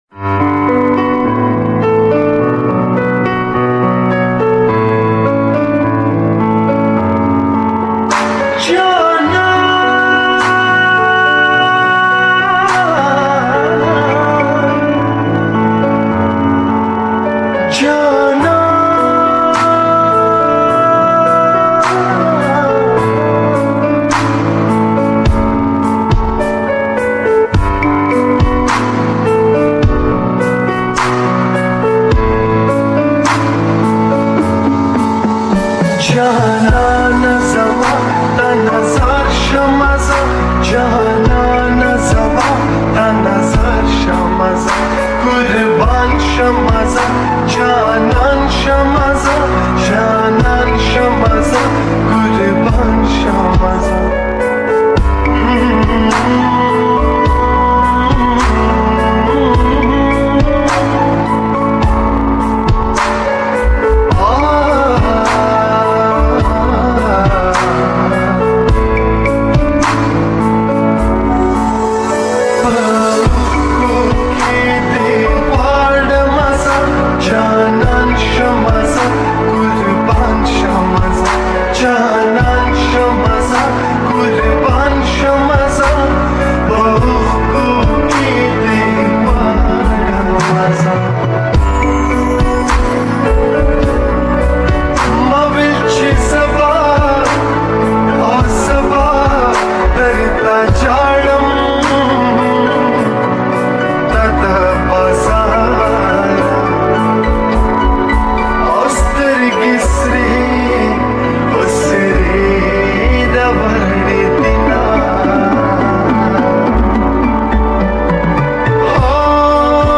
pashto full slowed song